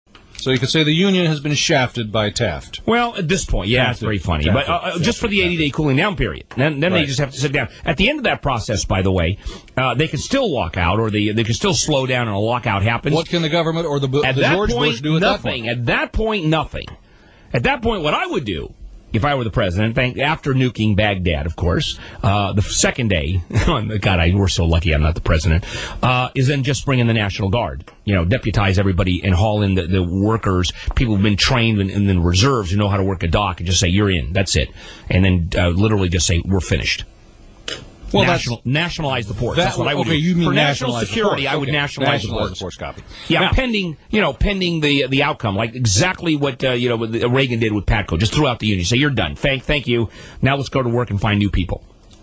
United States, Los Angeles
UnitedStates_LosAngeles.wav